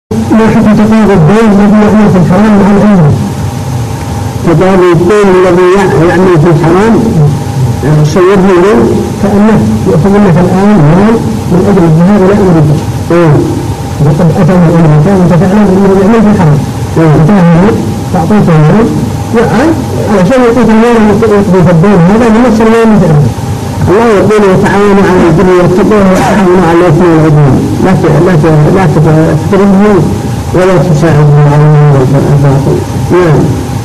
| فتاوى الشيخ مقبل بن هادي الوادعي رحمه الله